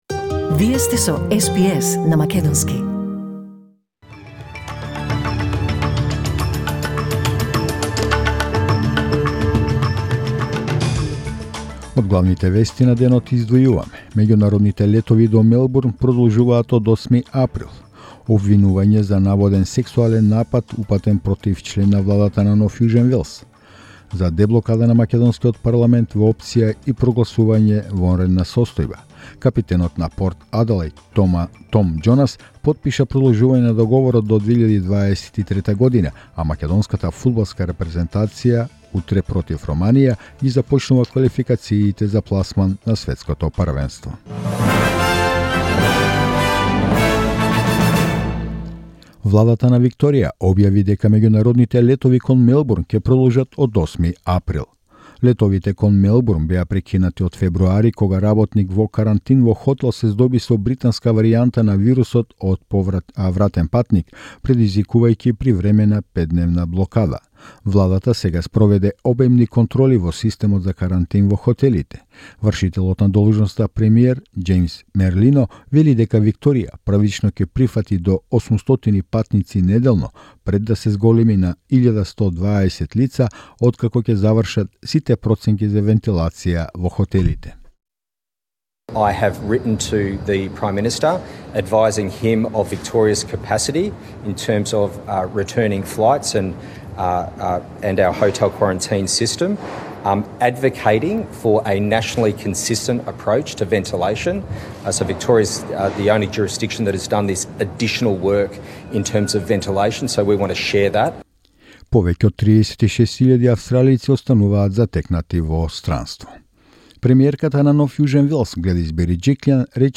SBS News in Macedonian 25 March 2021